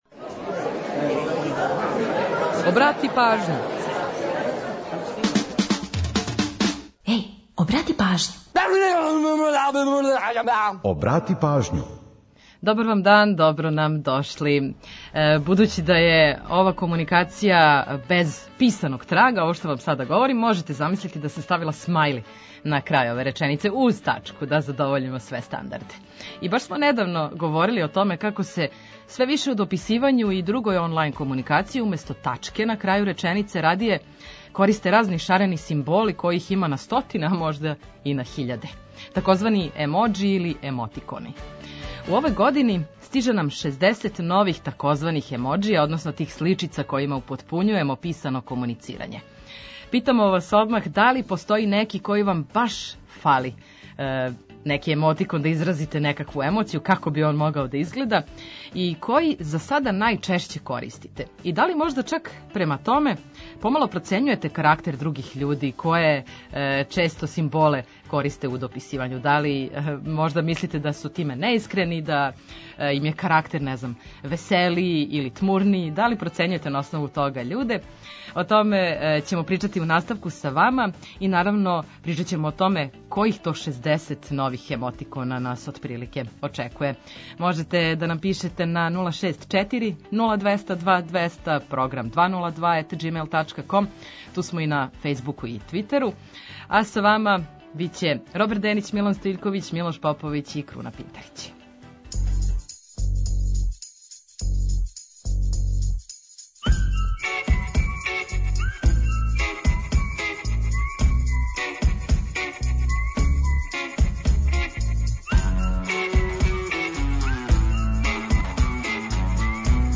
Сервисне информације и наш репортер са подацима о саобраћају помоћи ће многима у организовању дана, а „Културни водич” је ту да предложи које манифестације широм Србије можете да посетите. Ту су и музичке теме којима подсећамо на приче иза песама, славимо рођенданe музичара, синглова и албума, а ту је и пола сата резервисаних само за нумере из Србије и региона.